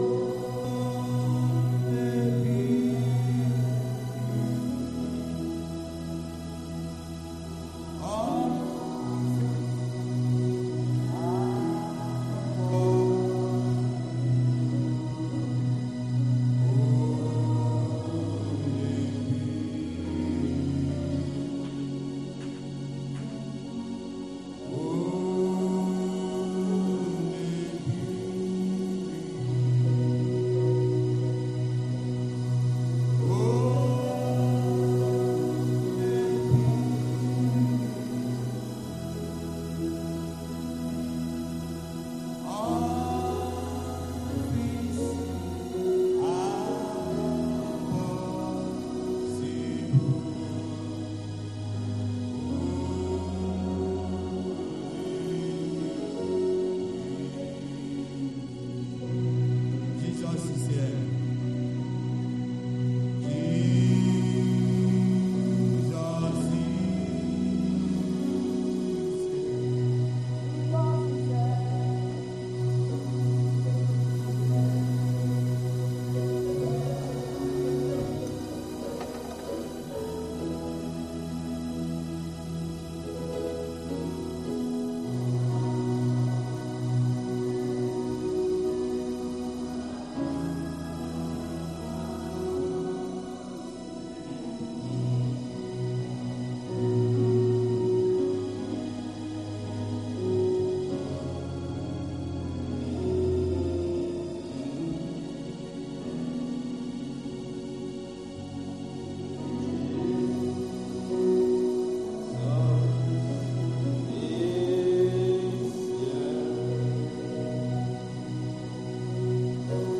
Sunday Afternoon Service 27/10/24